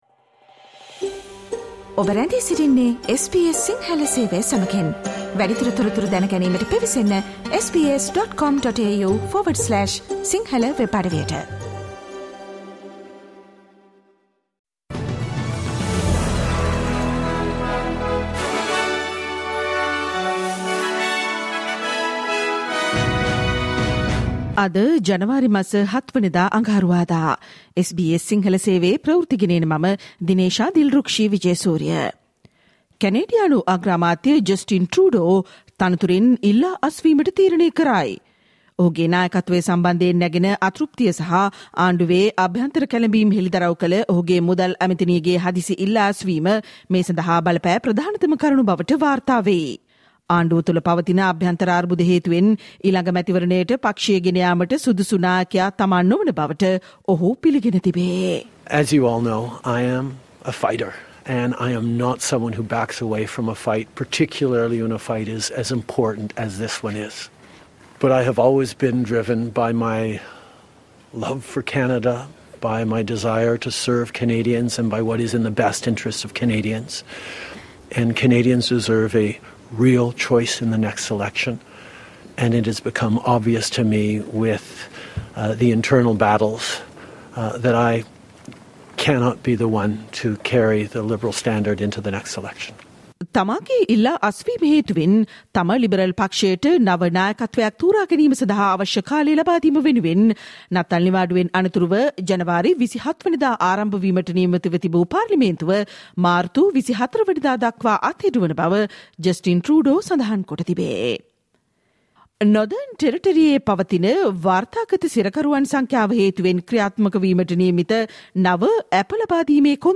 Australian news in Sinhala and brief foreign and sports news from SBS Sinhala Newsflash on Tuesday, 07 January 2025.